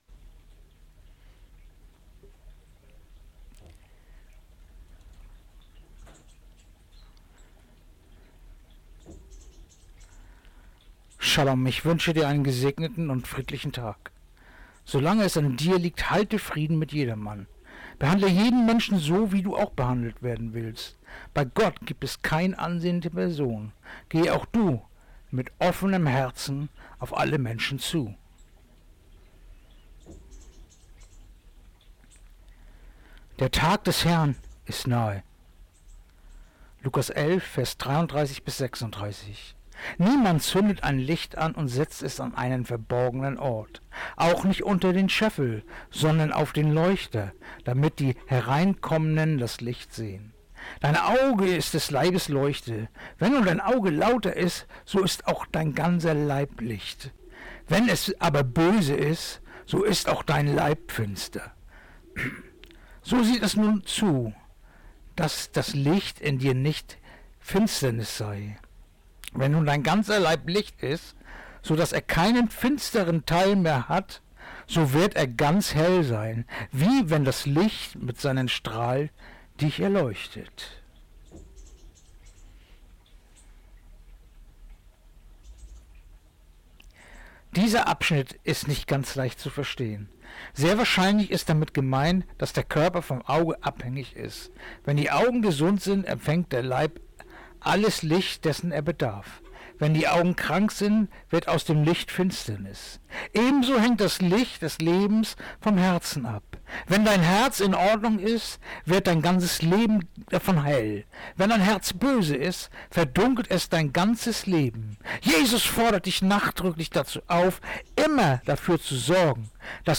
Andacht-vom-18.-Maerz-Lukas-11-33-36